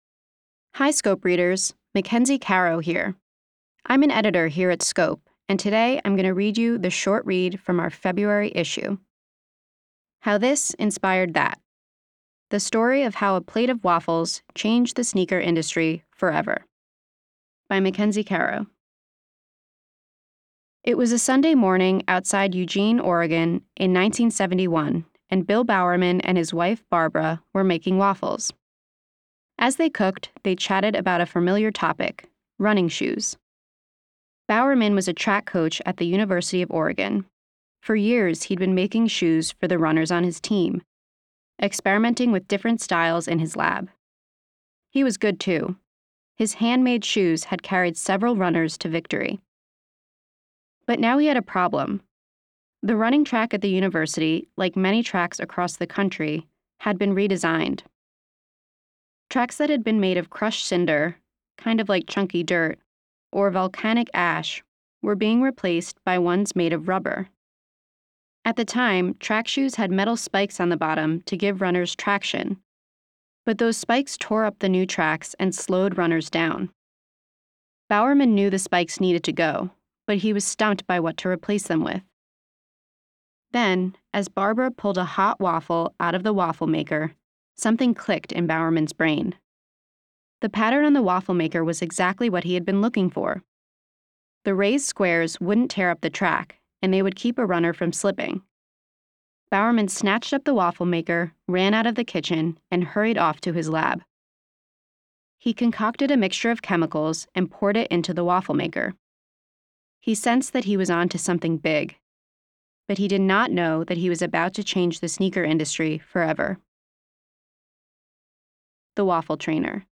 audio version while students follow along in their printed issues.